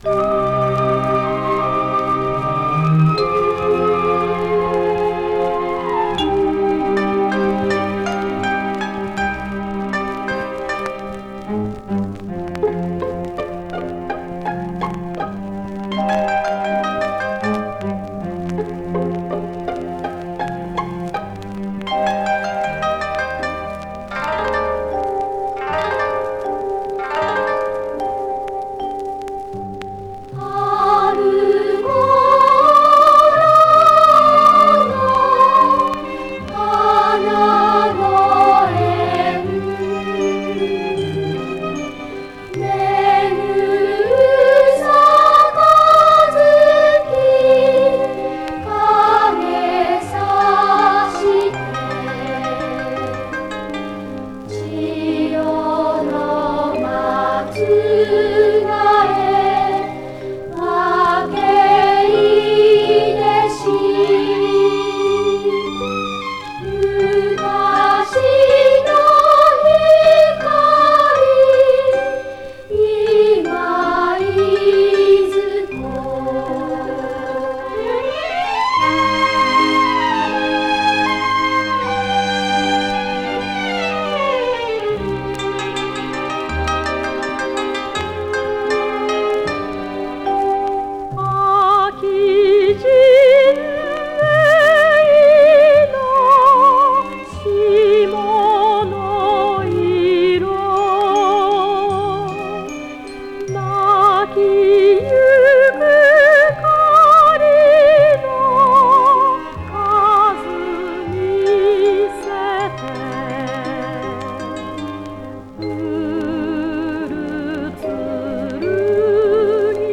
Жанр: Enka
в сопровождении традиционного японского инструмента кото.
Koto
Soprano Vocals